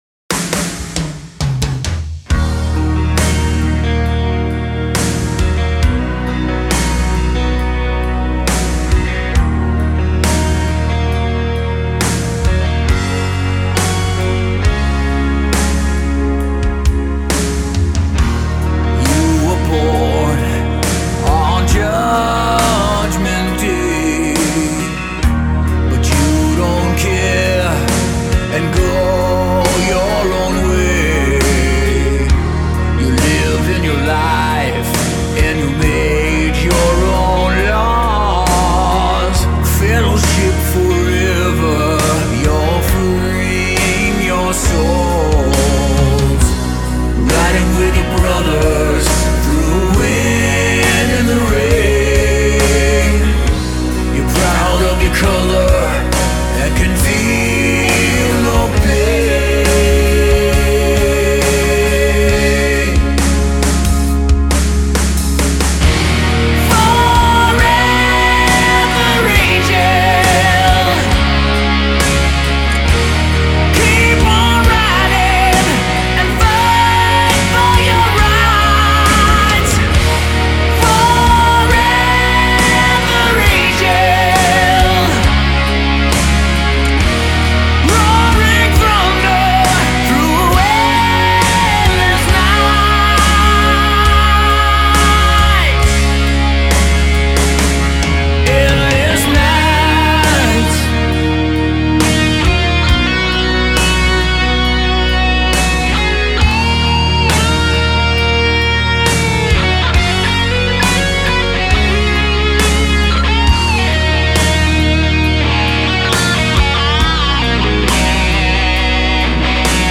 متال